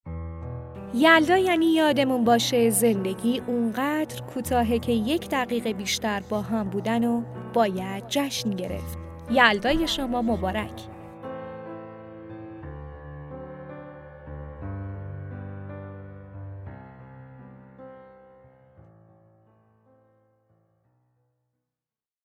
تبریک شب یلدا به دوست فایل صوتی